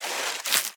Sfx_creature_penguin_land_get_up_01.ogg